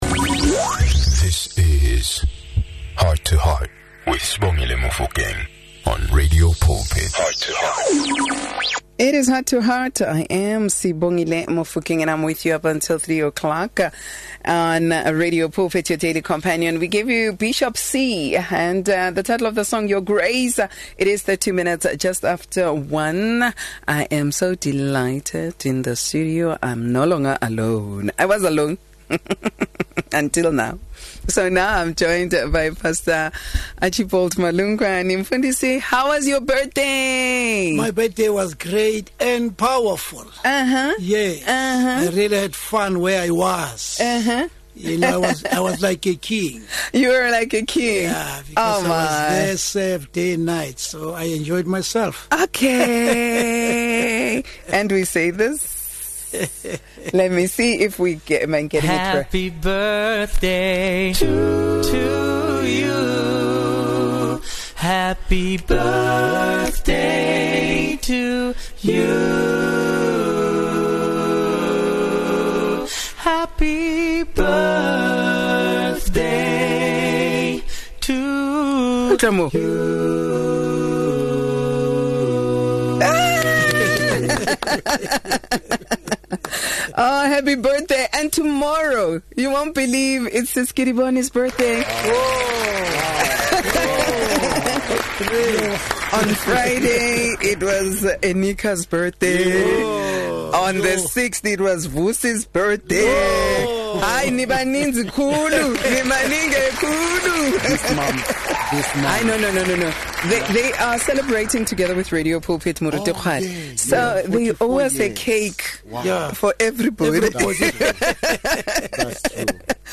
Heart to Heart is a magazine show on Radio Pulpit that brings you teachings, gospel music and advice.
To keep the content fresh, inspiring and from different perspectives, we have three presenters, each with their unique style.